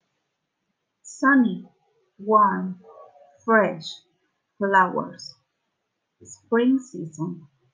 Flashcards con palabras e imágenes sobre las estaciones y palabras clave de elementos asociados del clima en ingles, puedes escuchar la pronunciación haciendo clic en el botón play.